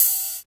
27 OP HAT.wav